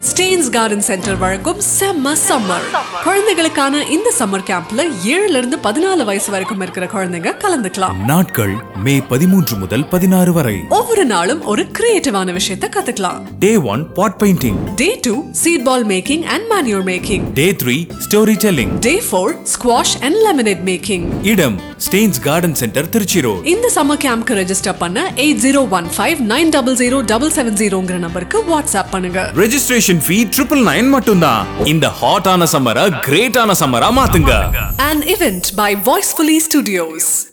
Stanes Garden Centre Kids Sema Summer – Radio Commercial